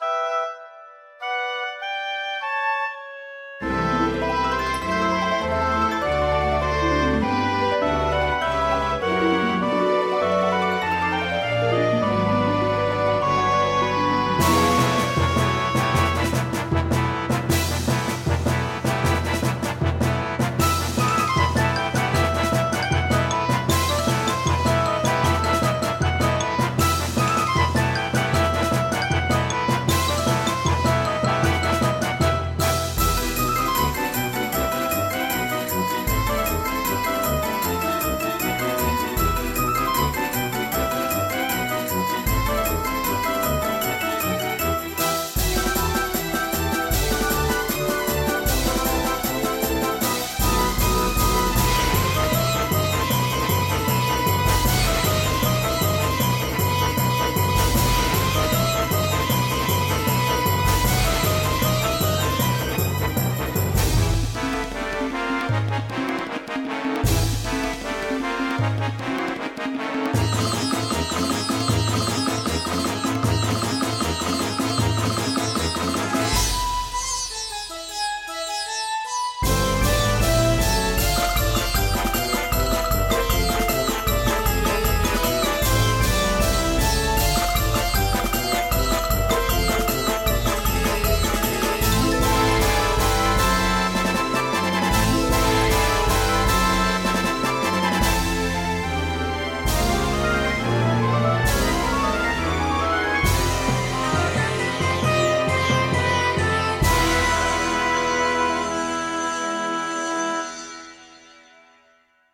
BPM100-155
Audio QualityMusic Cut